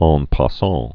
ɴ pä-säɴ)